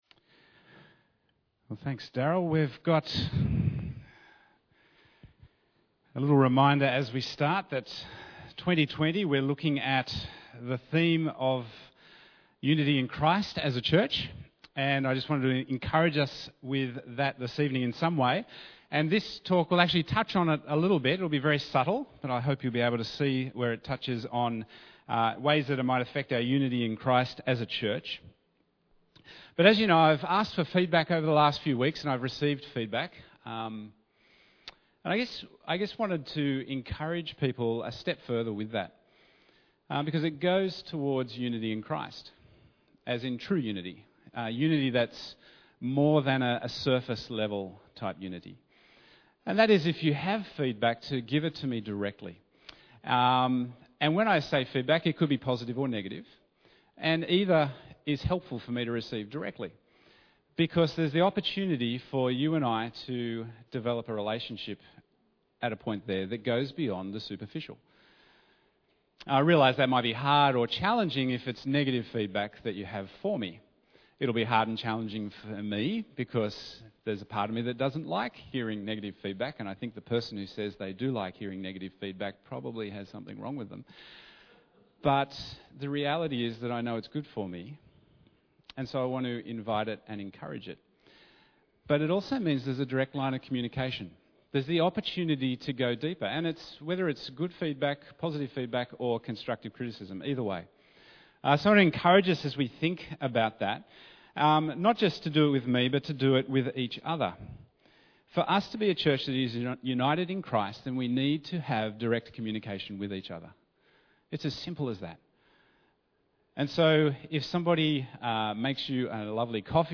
Bible Text: Luke 10:38-42 | Preacher